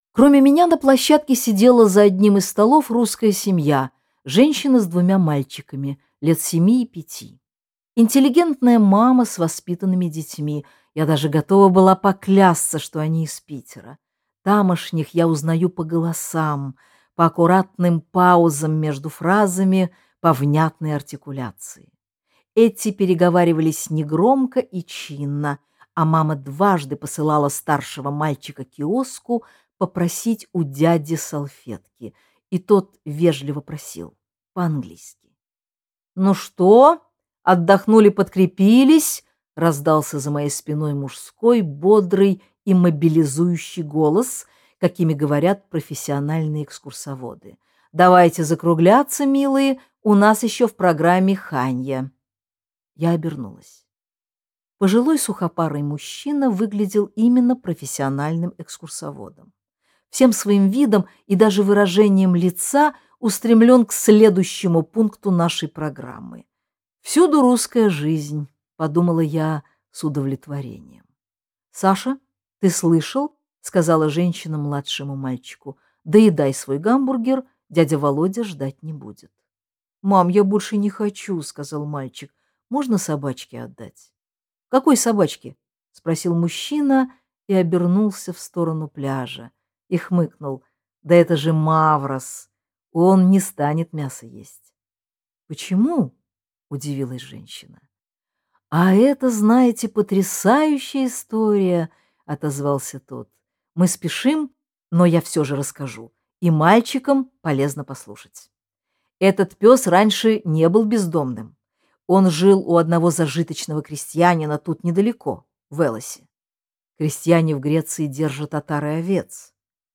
Аудиокнига Долгий летний день в синеве и лазури | Библиотека аудиокниг
Aудиокнига Долгий летний день в синеве и лазури Автор Дина Рубина Читает аудиокнигу Дина Рубина.